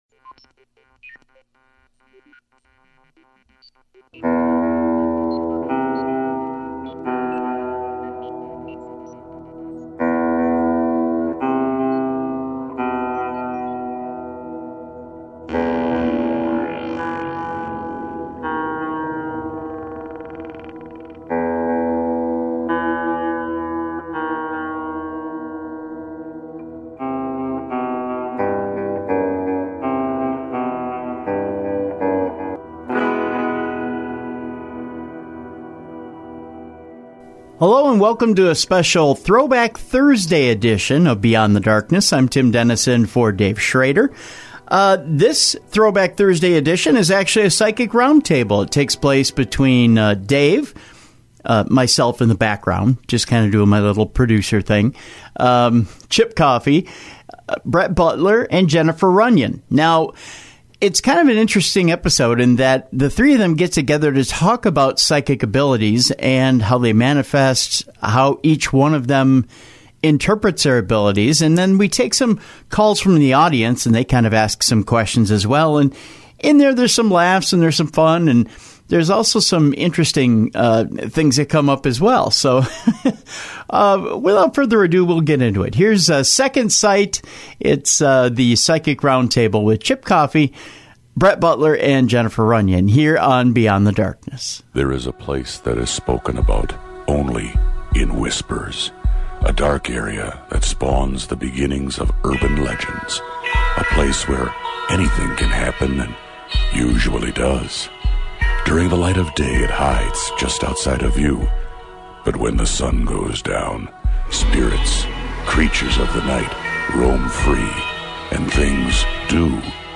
A Psychic Round table with celebrity guests in this Throwback Thursday episode from the Darkives.